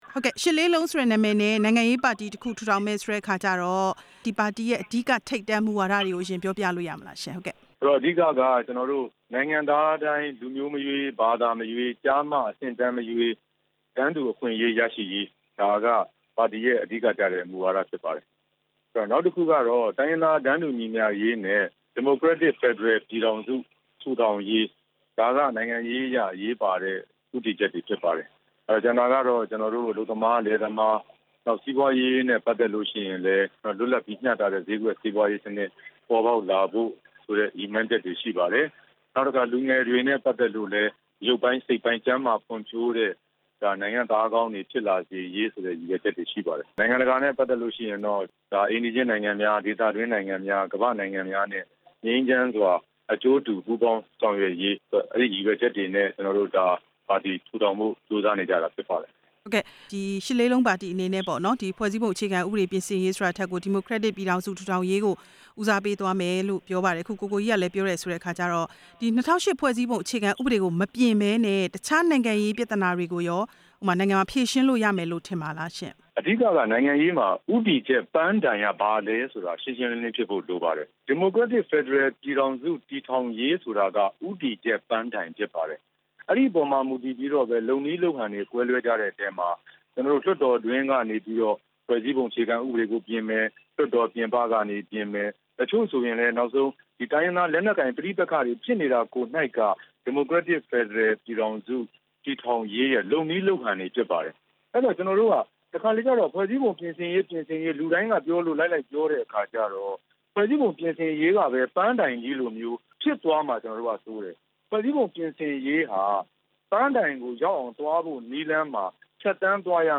ရှစ်လေးလုံးပါတီသစ်အကြောင်း ဦးကိုကိုကြီးနဲ့ မေးမြန်းချက်